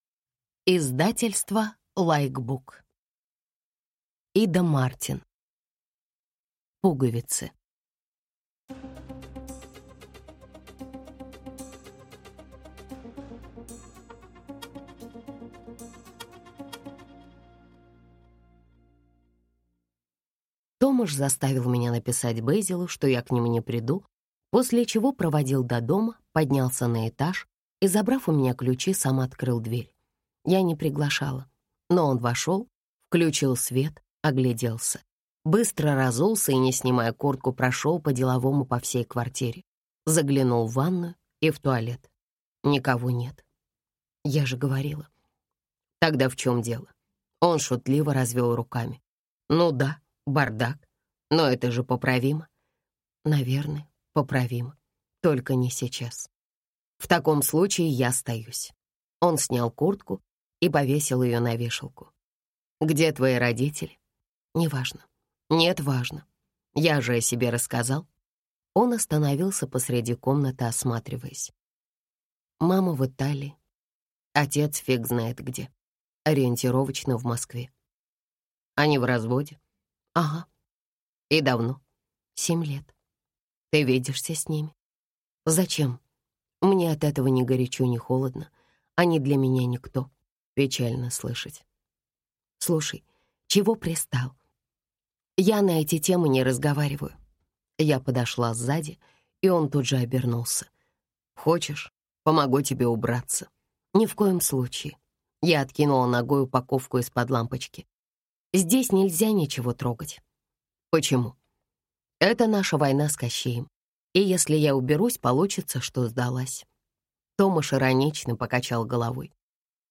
Аудиокнига Пуговицы | Библиотека аудиокниг